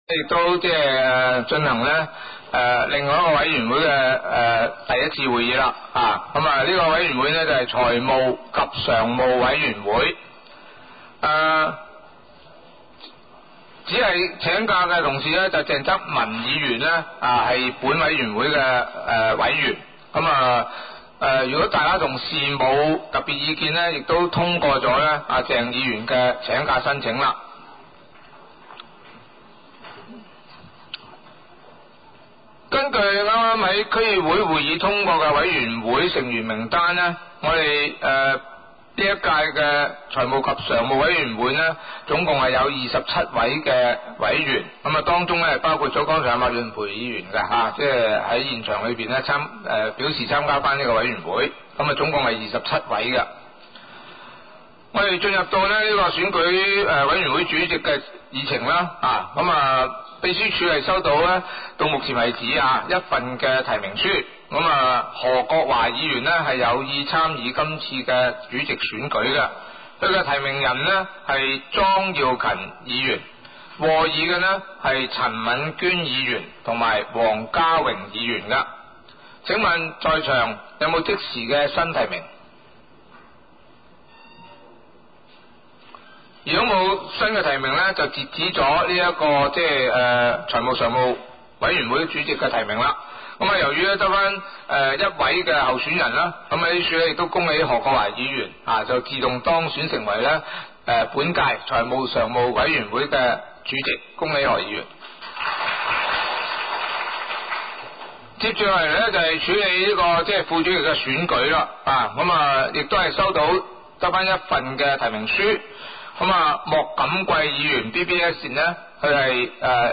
委员会会议的录音记录
地点: 沙田区议会会议室